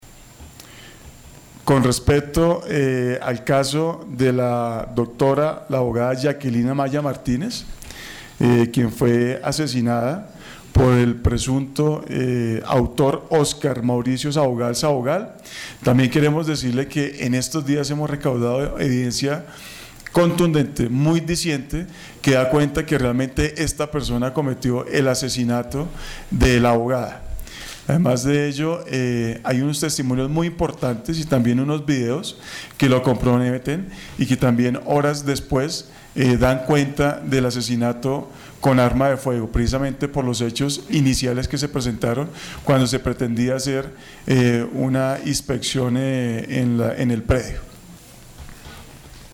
Rueda de prensa Director Nacional del CTI, Julián Quintana